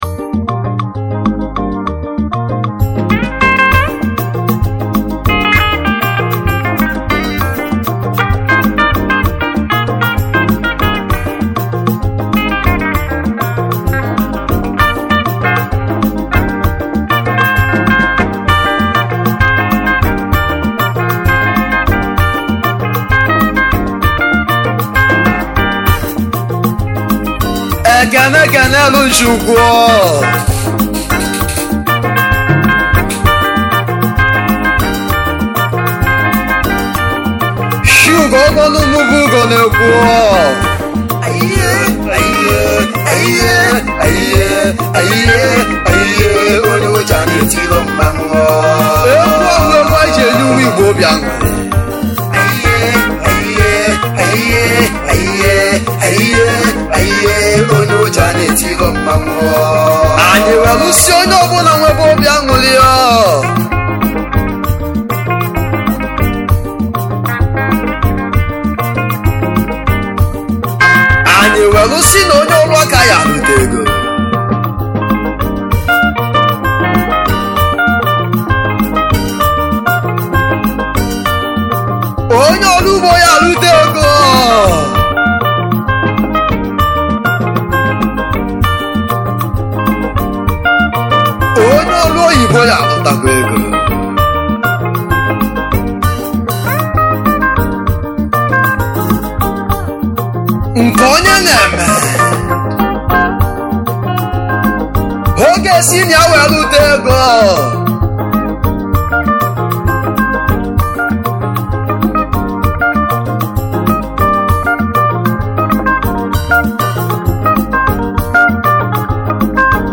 igbo highlife